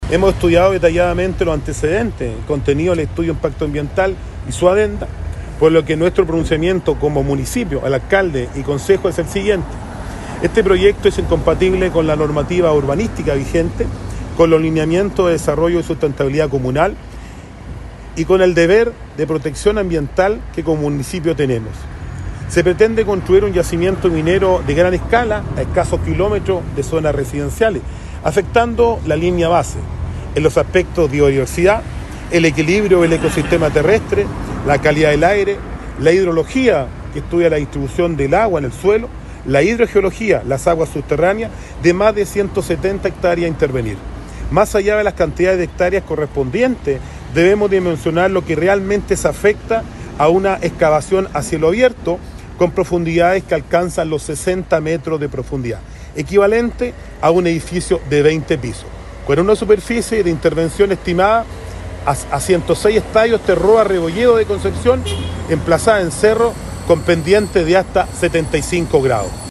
El alcalde enfatizó que el proyecto “es incompatible con la normativa urbanística vigente, con los lineamientos de desarrollo y sustentabilidad comunal, y con el deber de protección ambiental que como municipio tenemos”.